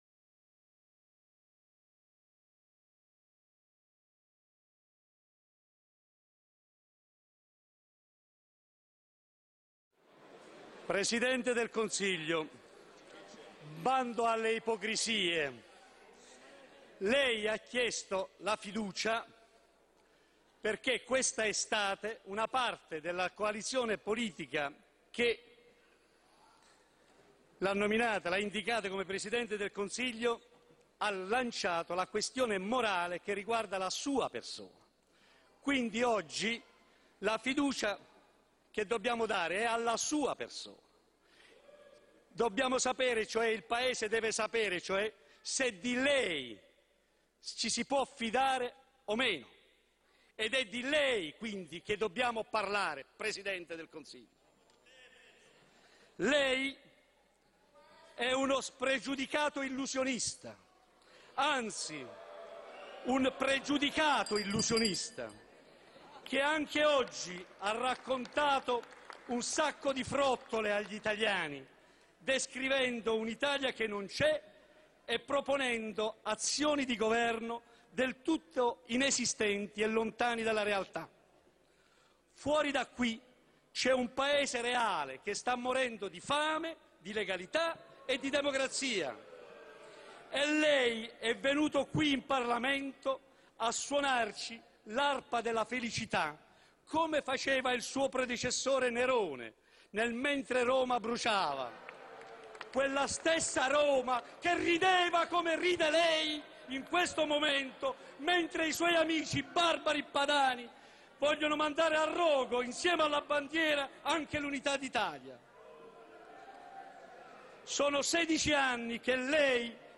Intervento Di Pietro parlamento